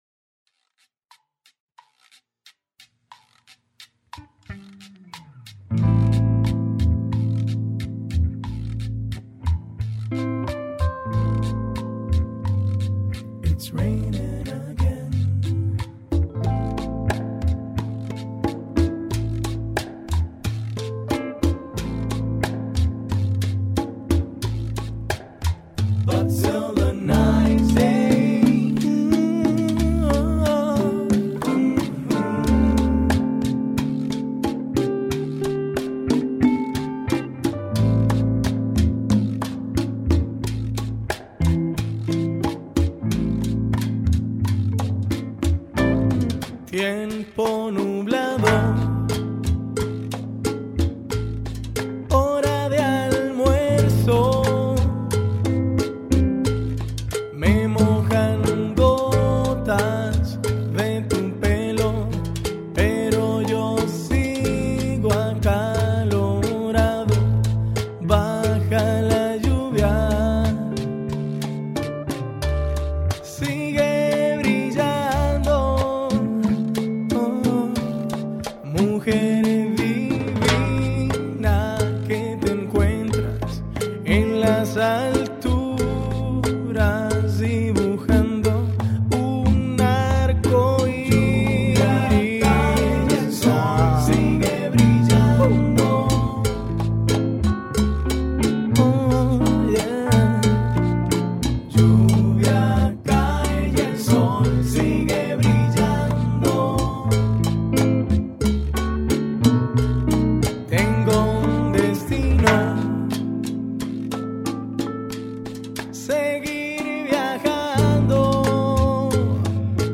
dance/electronic
World music